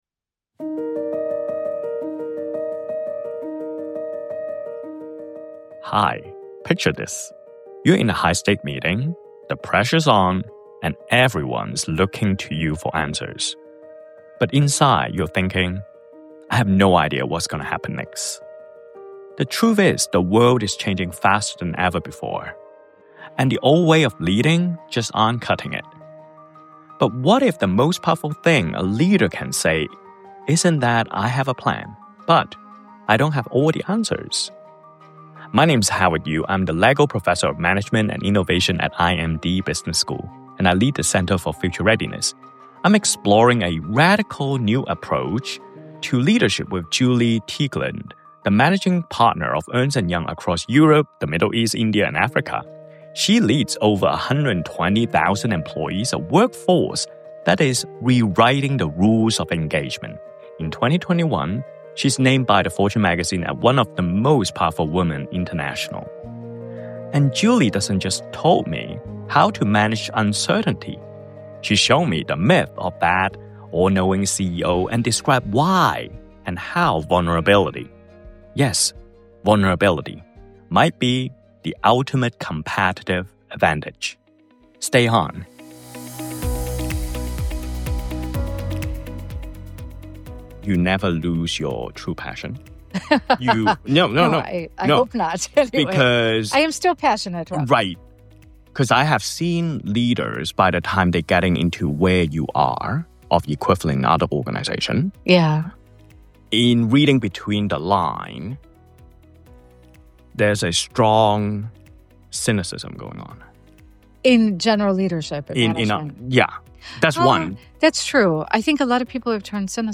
The Interview Podcast Series